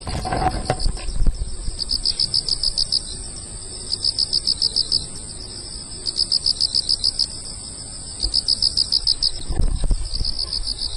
koorogi.mp3